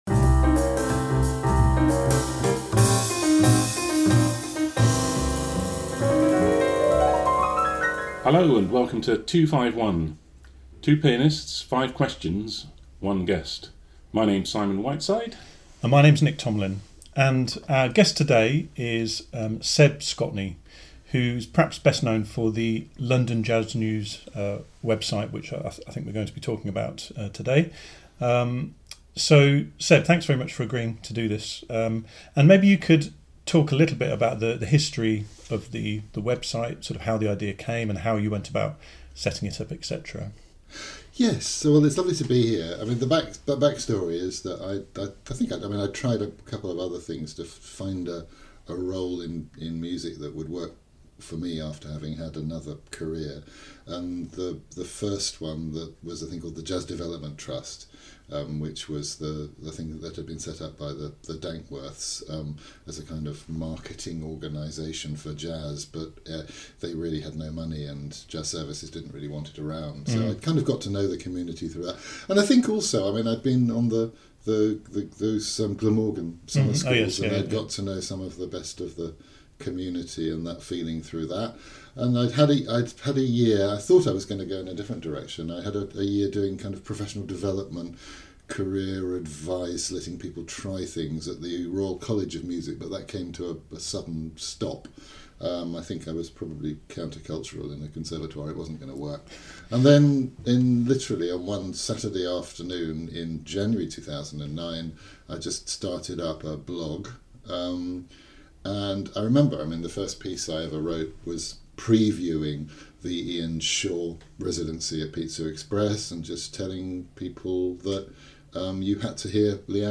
Show Notes This is our third interview with people who promote, facilitate and help proliferate jazz.